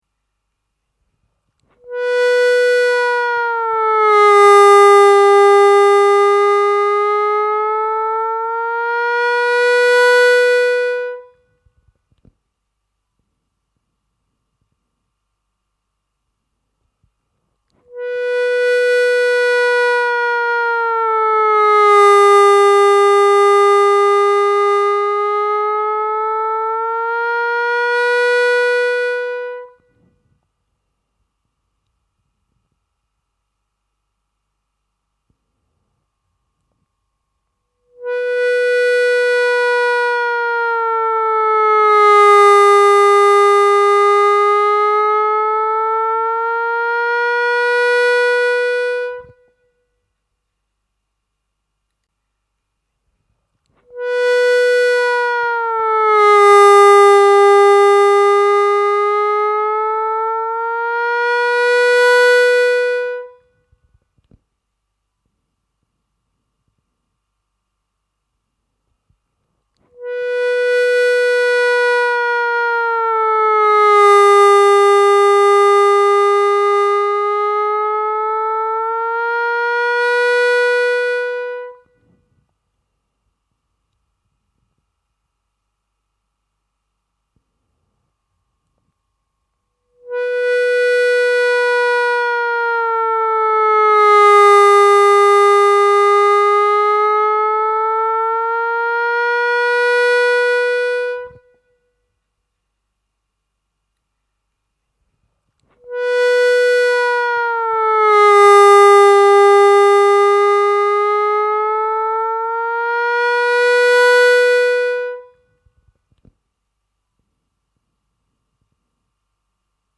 Звуковой файл для отработки бенда (в паузах играйте свой бенд на третьем отверстии)